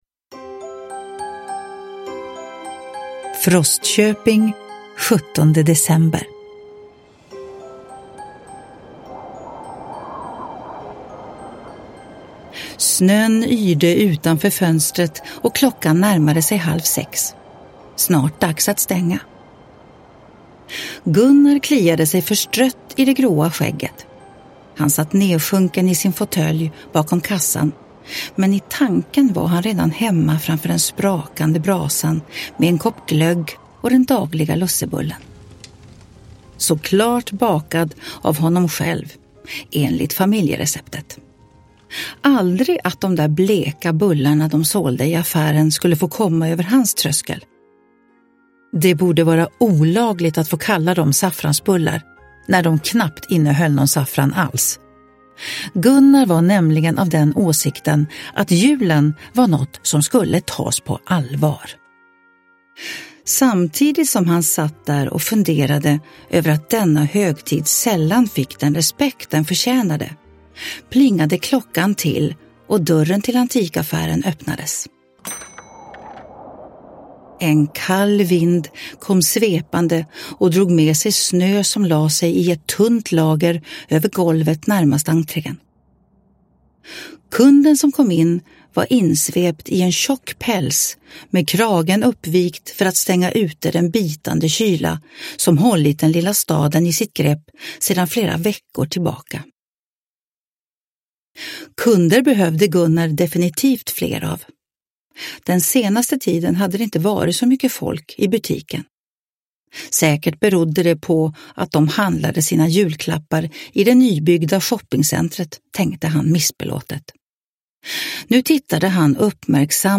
Mysteriet i Frostköping – Ljudbok – Laddas ner